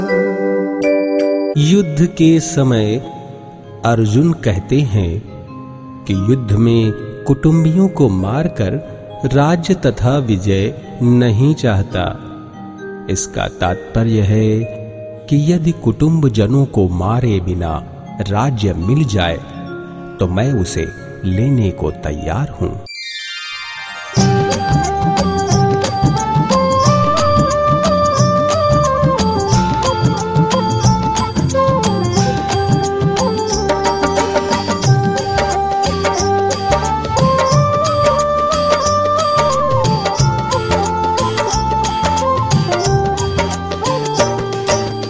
His sweet and warm, rich and distinctively English and Hindi Voice has enhanced the image of many of today’s leading brands .
Sprechprobe: Industrie (Muttersprache):